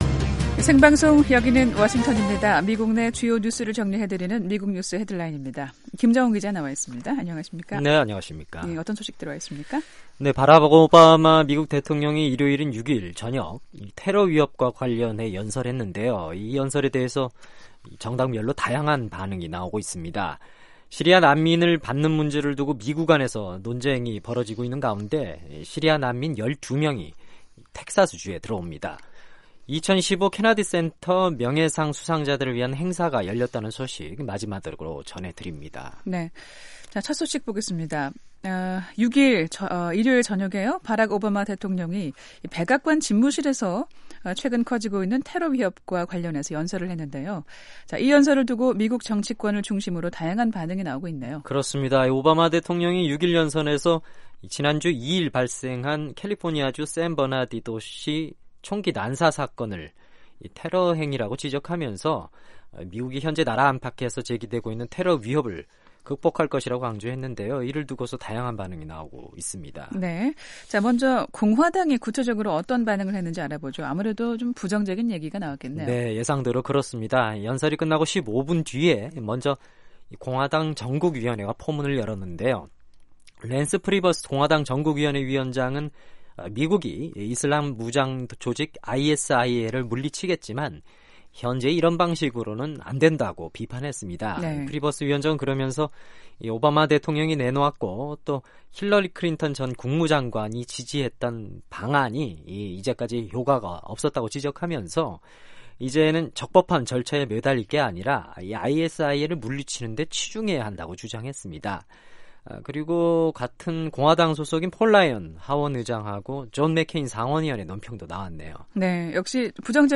미국 내 주요 뉴스를 정리해 드리는 ‘미국 뉴스 헤드라인’입니다. 바락 오바마 미국 대통령이 일요일 (6일) 저녁 테러위협과 관련해 연설했는데요.